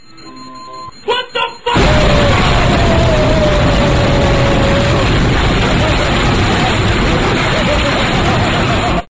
Wtf Boom Sound Effect Free Download